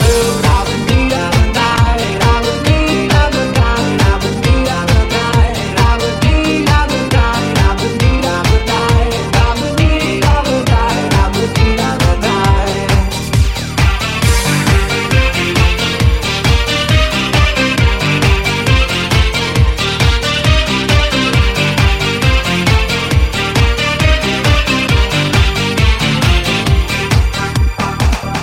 Genere: pop, dance, elettronica, successi, remix